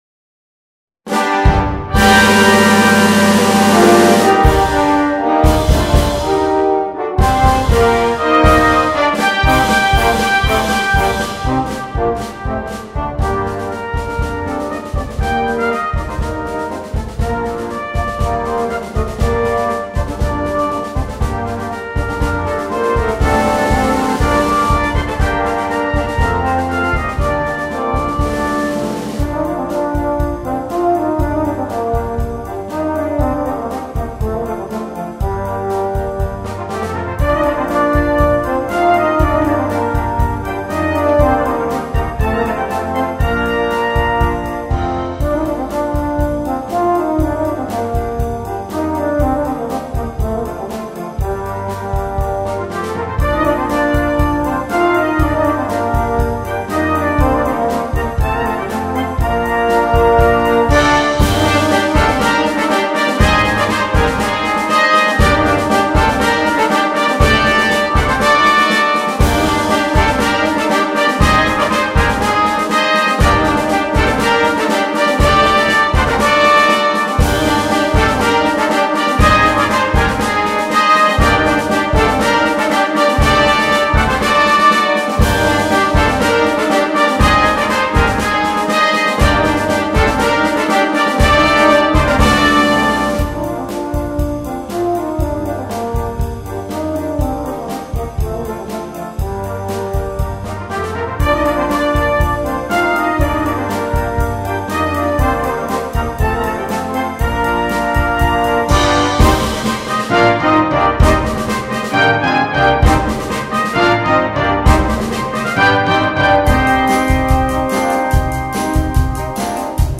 2. Brass Band
sans instrument solo
Musique légère